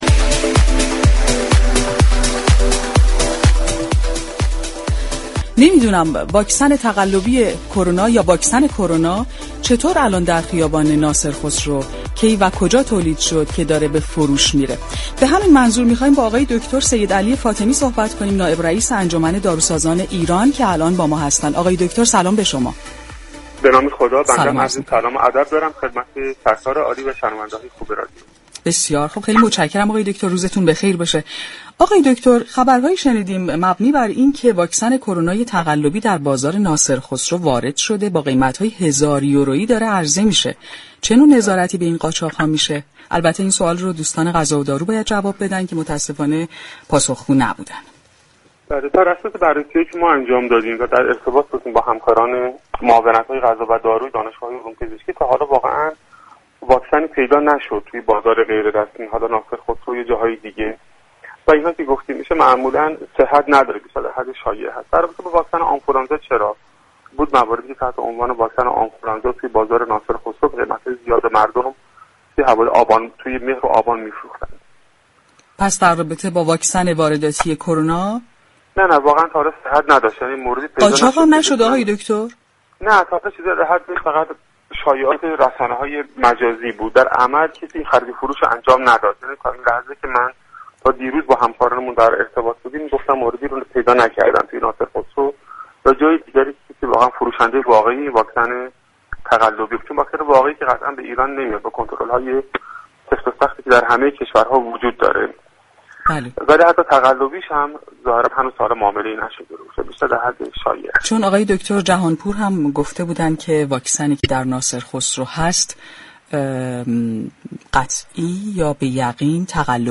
گفت‌وگو با برنامه « تهران ما سلامت» رادیو تهران